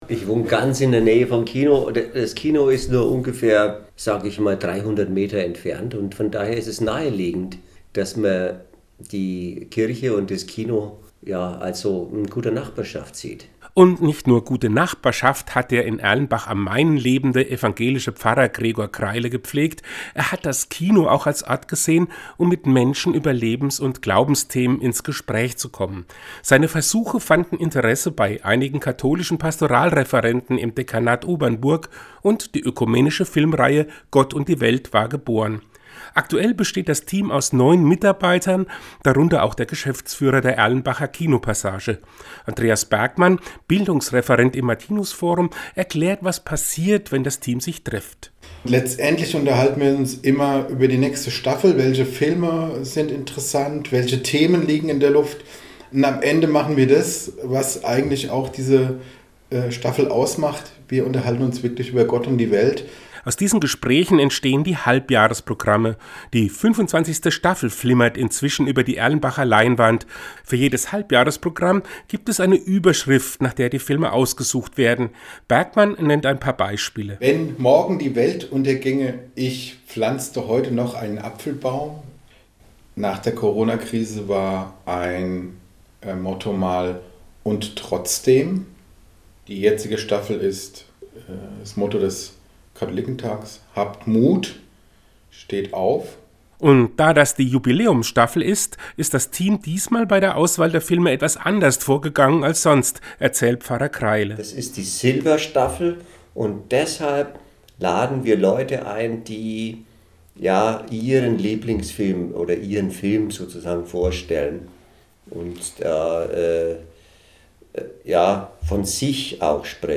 hat sich zum Jubiläum mit zwei Mitgliedern des Kinoteams getroffen.